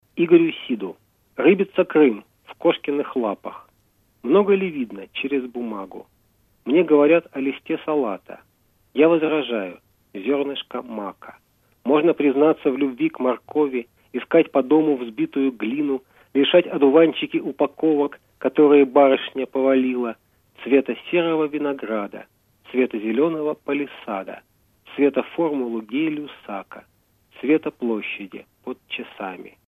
читает стихи о Крыме